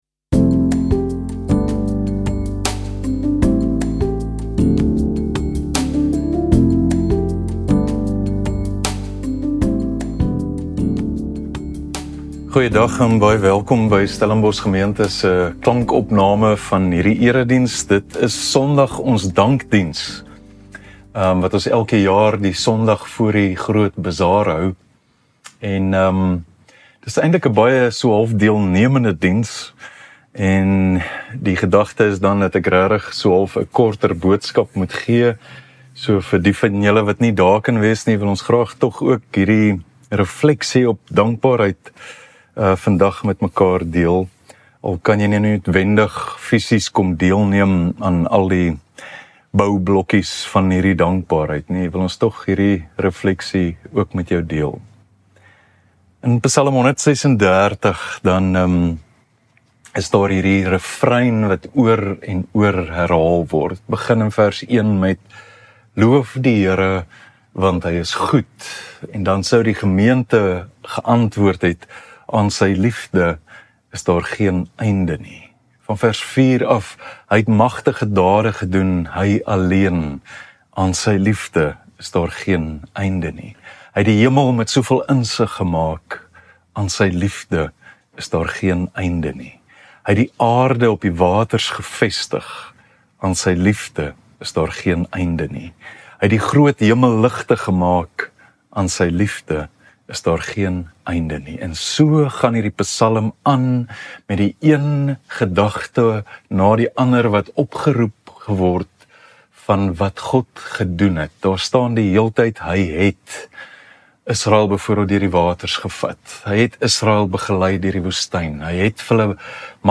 Stellenbosch Gemeente Preke 16 November 2025 || Want Aan U...